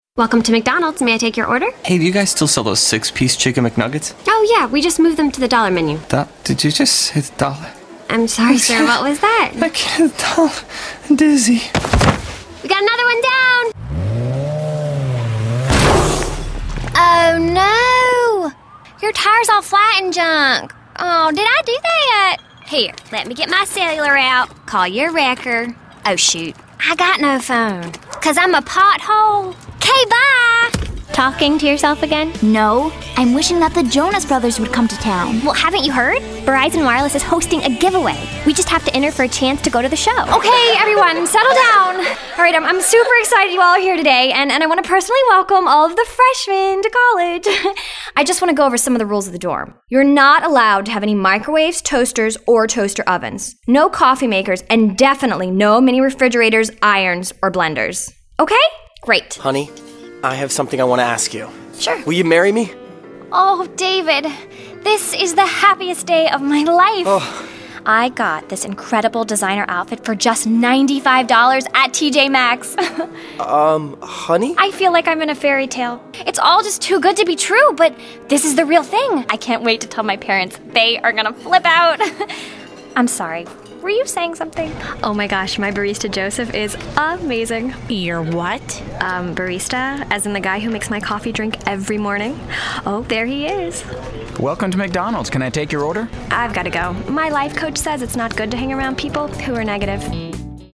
Voice-Over Demos
Commercial Demo: